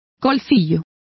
Complete with pronunciation of the translation of ragamuffin.